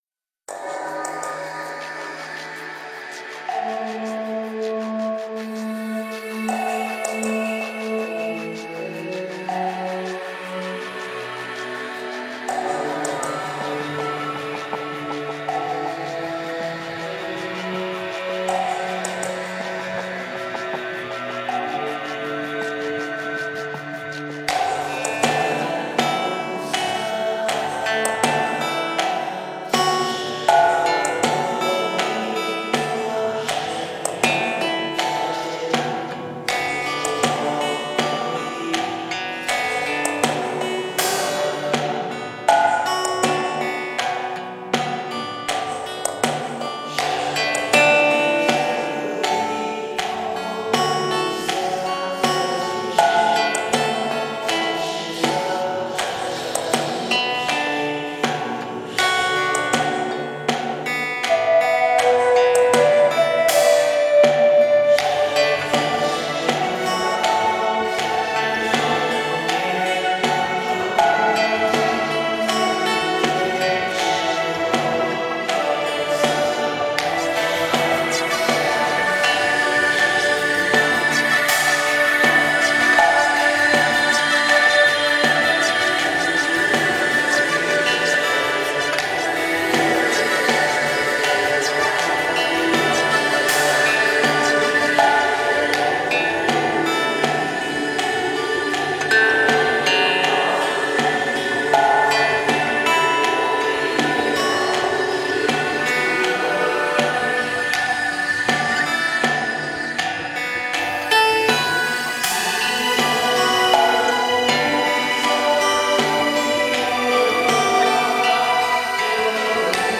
佛音 冥想 佛教音乐 返回列表 上一篇： 毛毛虫也会变蝴蝶(音乐